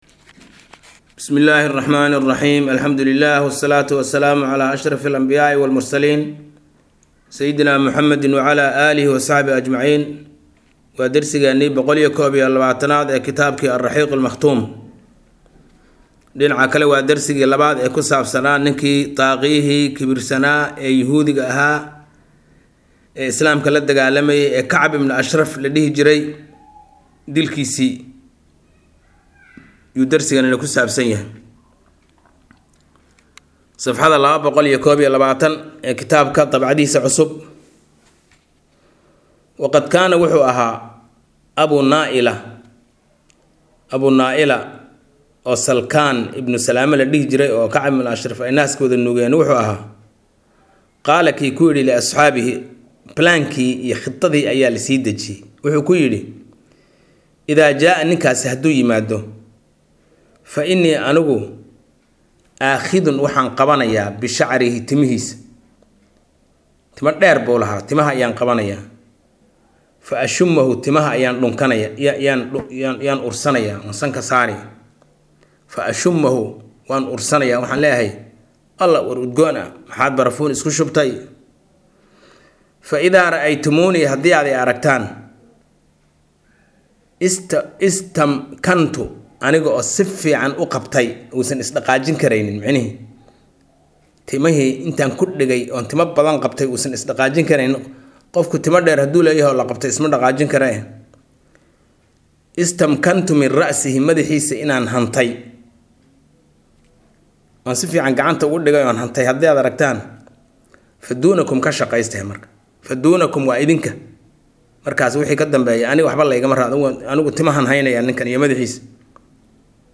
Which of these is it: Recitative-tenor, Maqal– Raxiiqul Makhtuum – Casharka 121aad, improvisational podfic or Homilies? Maqal– Raxiiqul Makhtuum – Casharka 121aad